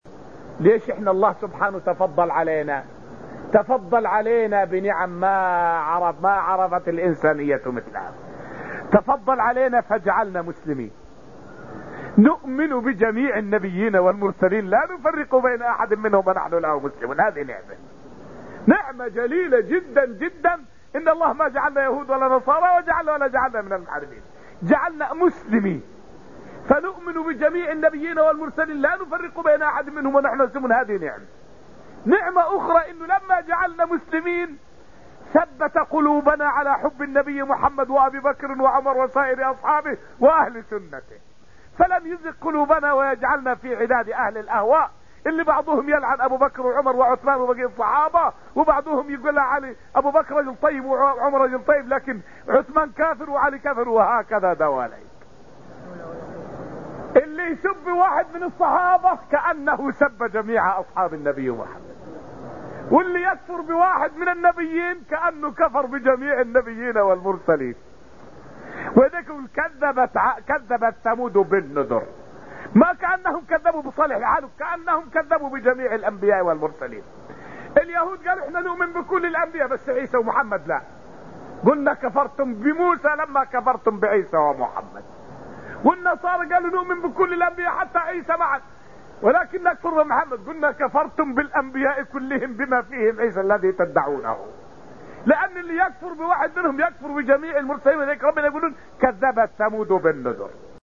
فائدة من الدرس الثاني من دروس تفسير سورة الطور والتي ألقيت في المسجد النبوي الشريف حول بيان أن المسلم على بصيرة والكافر في خوض وعماية.